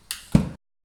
main Divergent / mods / Hideout Furniture / gamedata / sounds / ambient / gas_lamp / turn_on.ogg 21 KiB (Stored with Git LFS) Raw Permalink History
turn_on.ogg